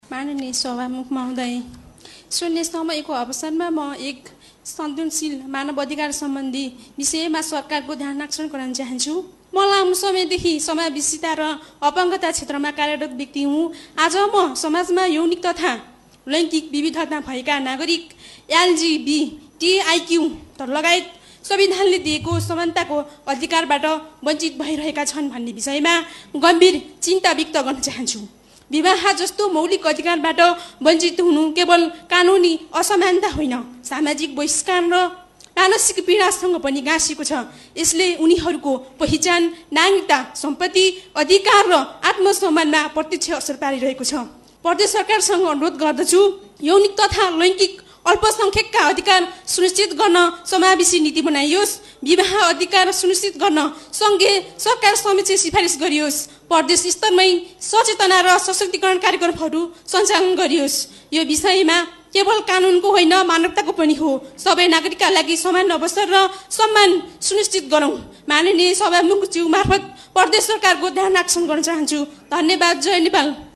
नेपाली काँग्रेसकी सांसद थापाले प्रदेशसभा बैठकको शून्य समयमा यौनिक तथा लैङ्गिक विविधता भएका नागरिक एलजीबीटीआईक्यू लगायत संविधानले दिएको समानताको अधिकारबाट वञ्चित भइरहेका छन् भन्ने विषयमा गम्भीर चिन्ता व्यक्त गरिन।